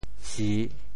潮语发音
si3.mp3